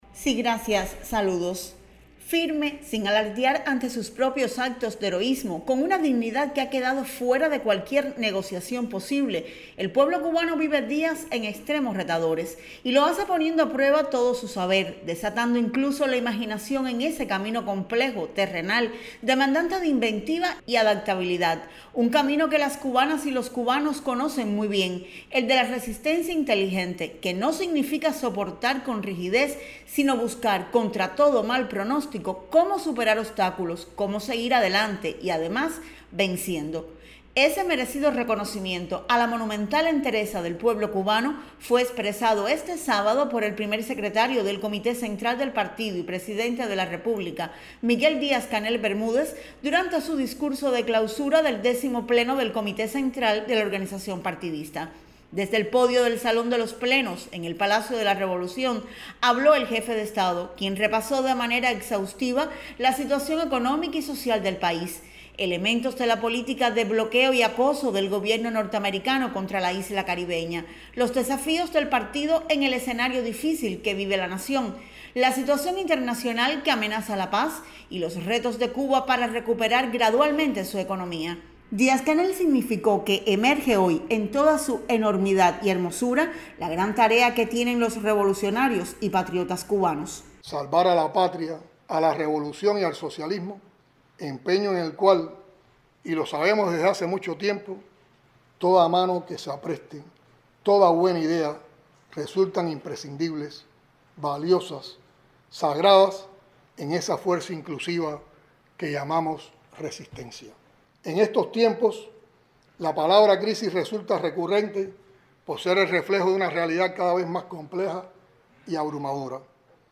Un merecido reconocimiento a la monumental entereza del pueblo cubano, fue hecho este sábado, por el Primer Secretario del Comité Central del Partido Comunista y Presidente de la República, Miguel Díaz-Canel Bermúdez, durante su discurso de clausura del X Pleno del Comité Central de la organización partidista
version_discurso_dc.mp3